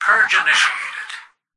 "Purge initiated" excerpt of the reversed speech found in the Halo 3 Terminals.